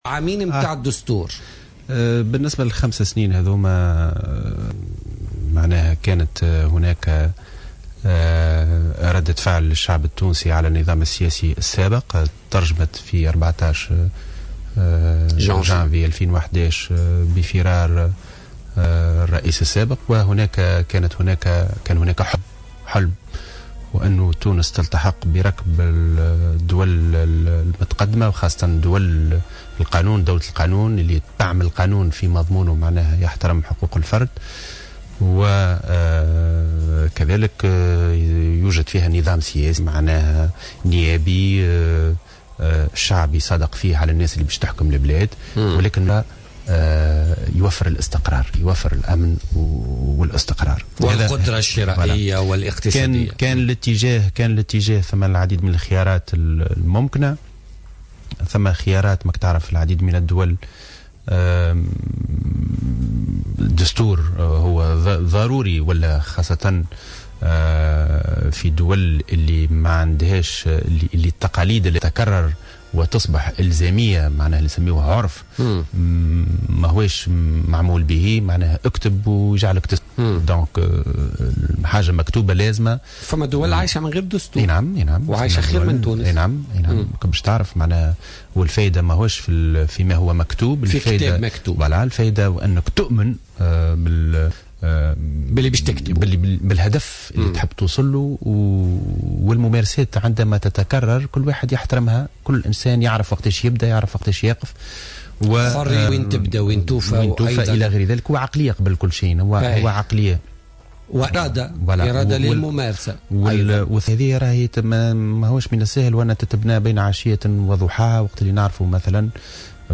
ضيف برنامج بوليتكا